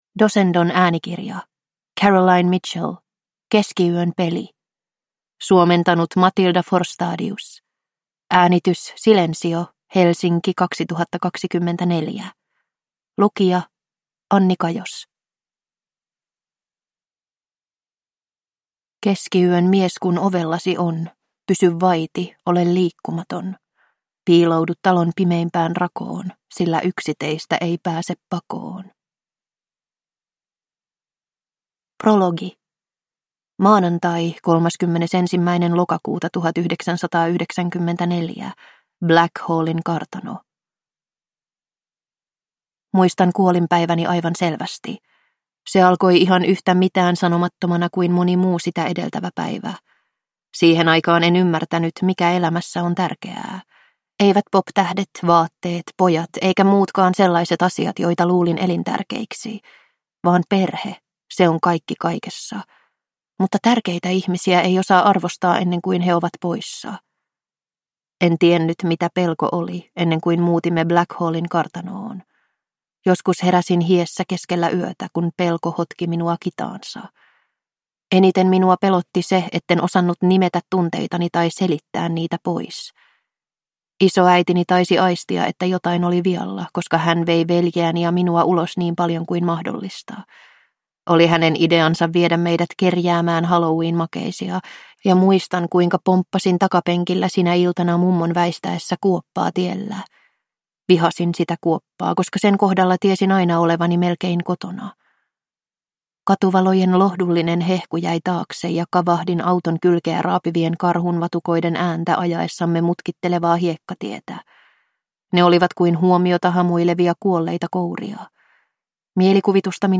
Keskiyön peli – Ljudbok